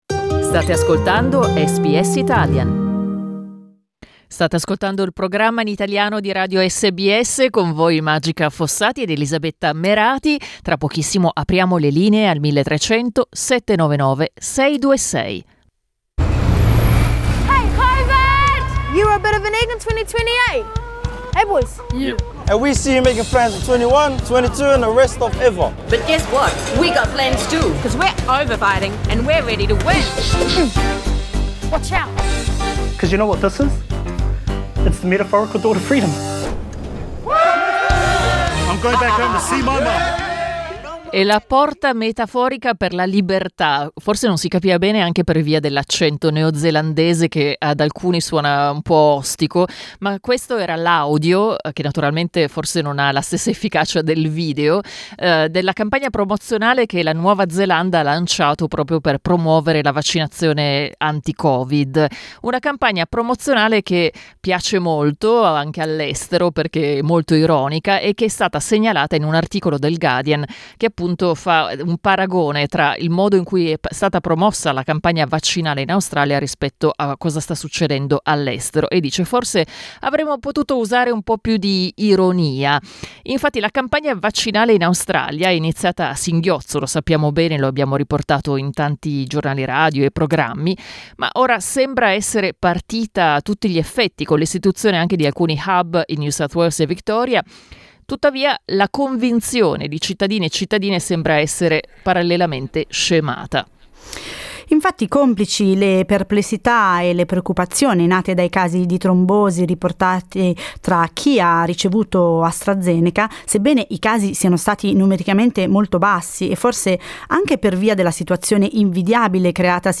Ne abbiamo parlato con alcuni ospiti e ascoltatori.